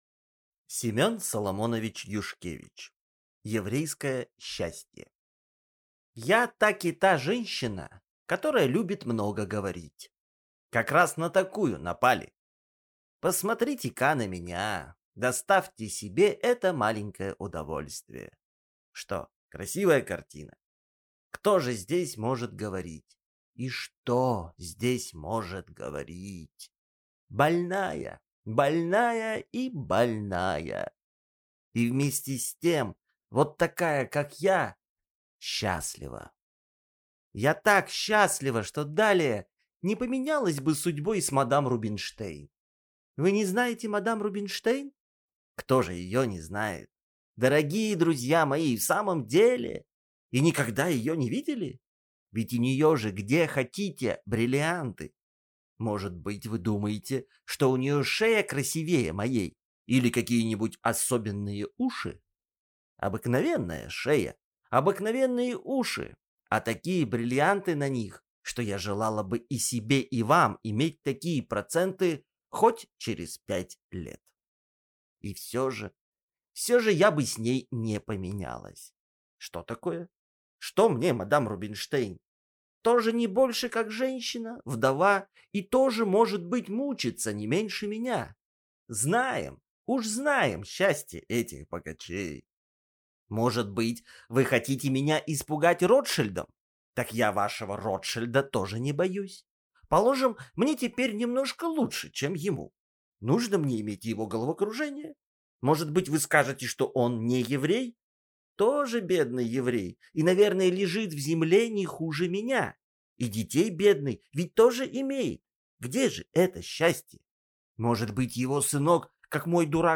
Аудиокнига Еврейское счастье | Библиотека аудиокниг